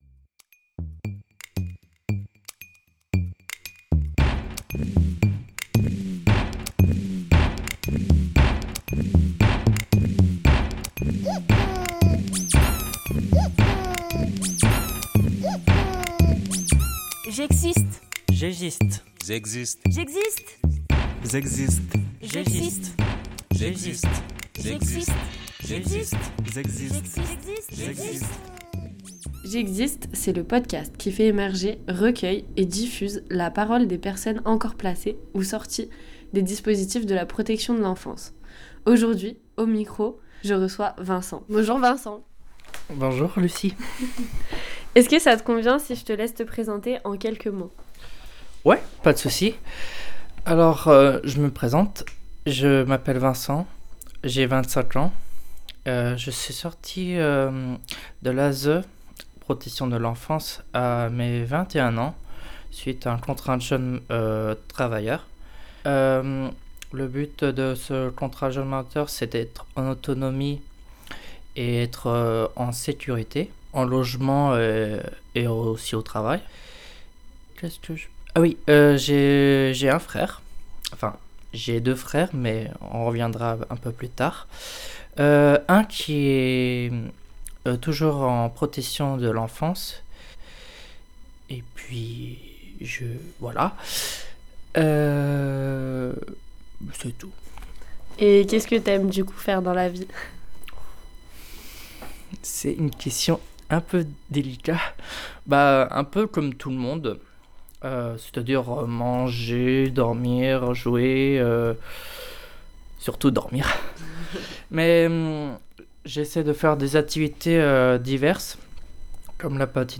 Témoignages recueillis au micro
Jingle réalisé par les adhérents de l’ADEPAPE Repairs ! 44